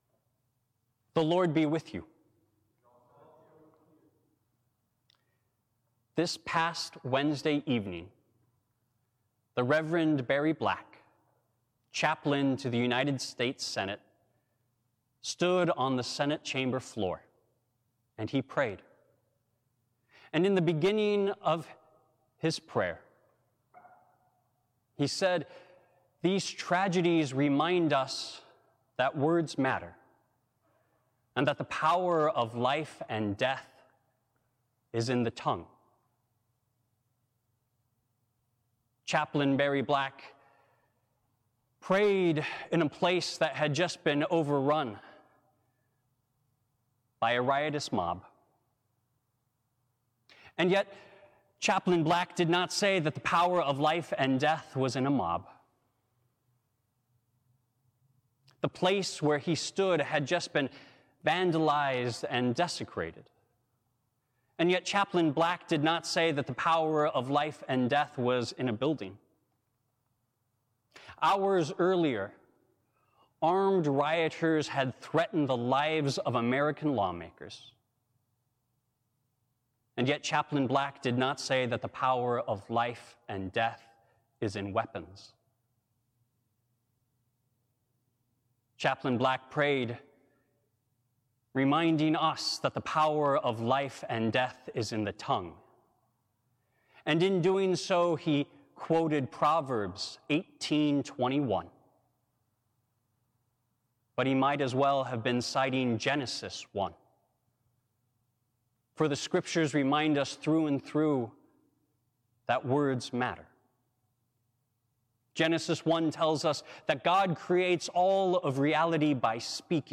Sermons from Faith Lutheran Church | Faith Lutheran Church